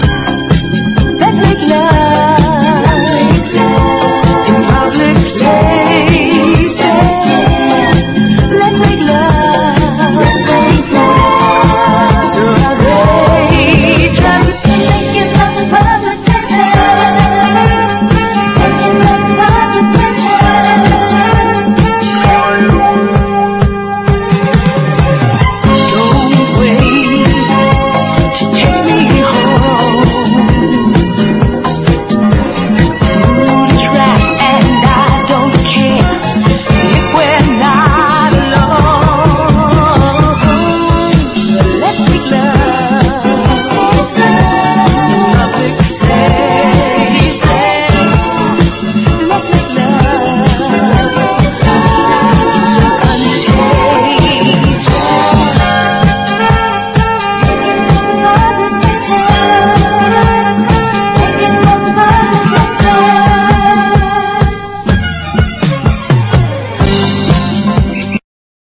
SOUL / SOUL / 60'S / NORTHERN SOUL